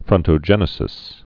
(frŭntō-jĕnĭ-sĭs)